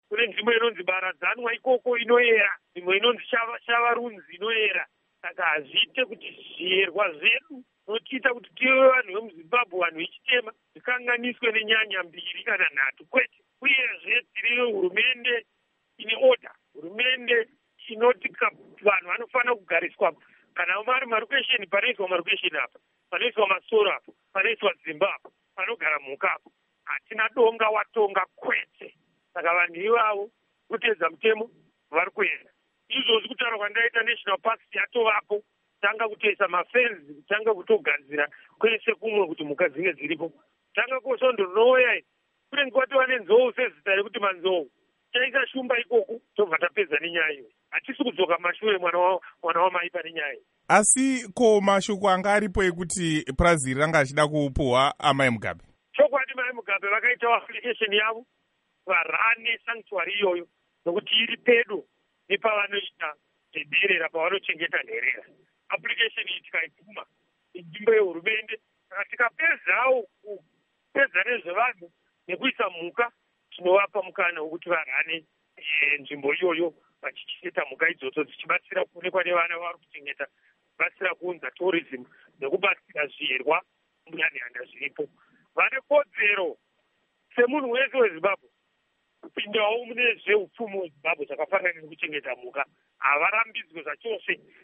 Hurukuro naVaMartin Dinha